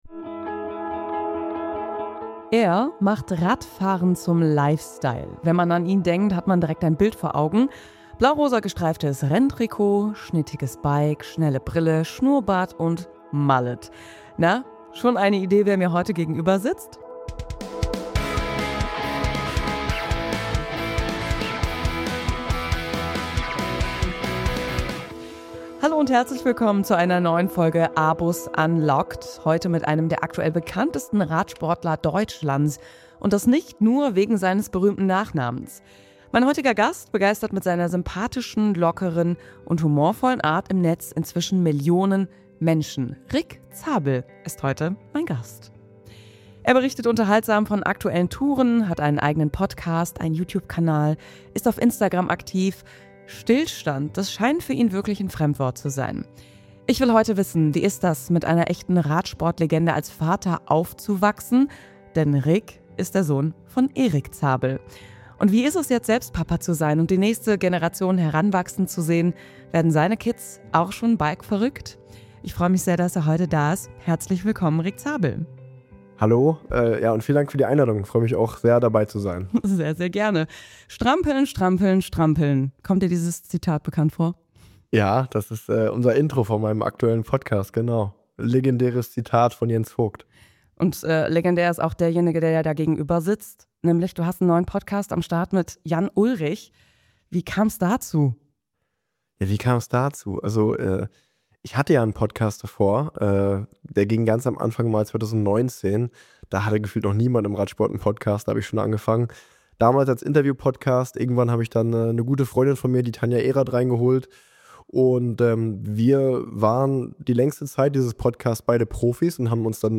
Rick spricht offen über seinen Abschied vom Profizirkus, die Beziehung zu seinem Vater Erik Zabel, seine neue Rolle als Papa – und warum Stillstand für ihn nie eine Option war.